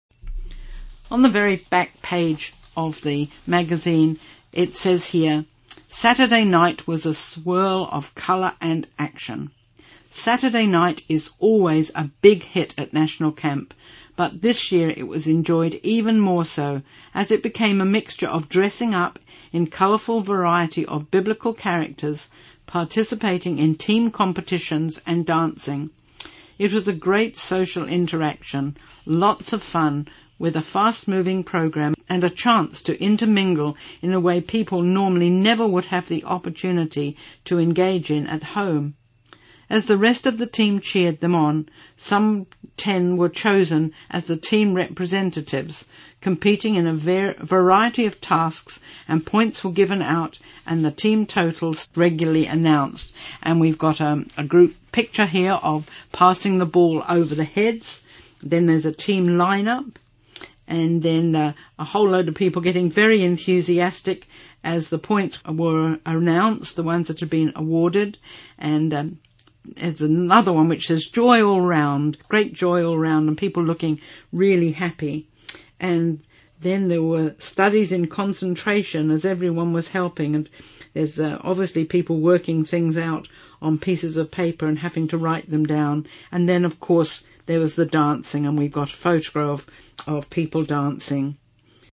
Genre: Speech..Released: 2016.